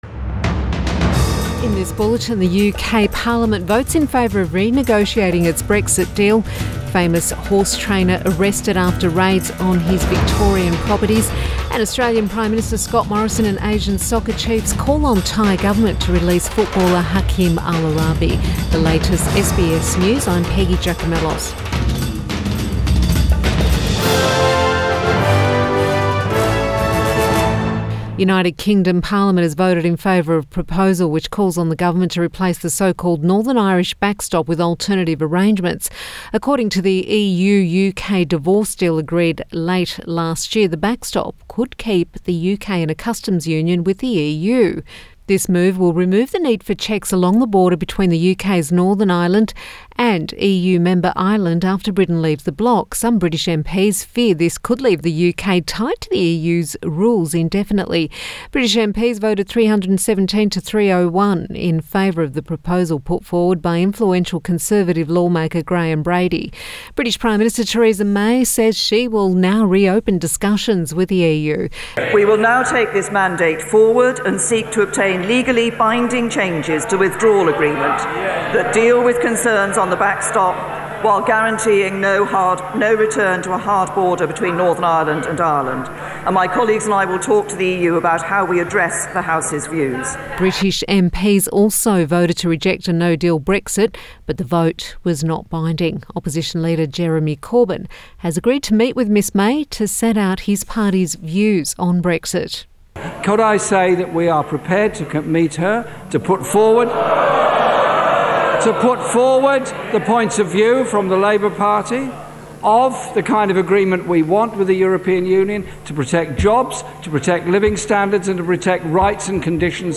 Midday Bulletin Jan 30